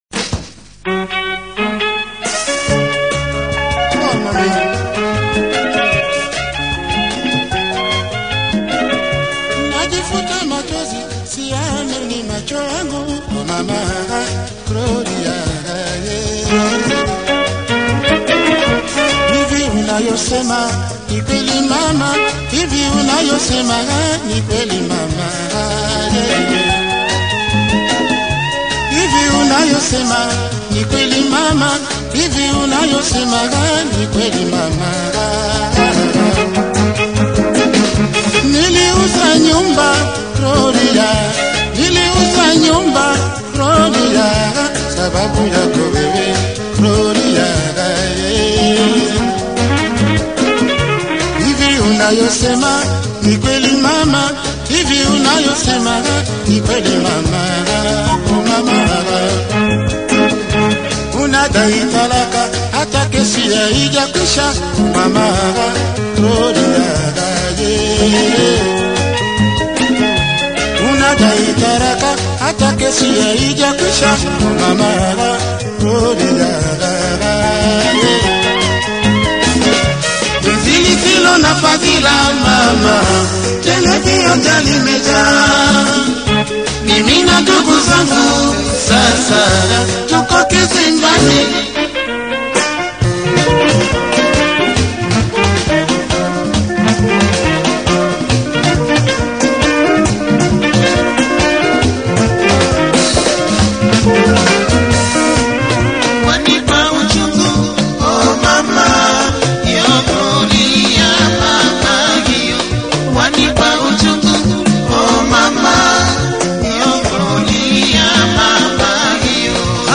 Rhumba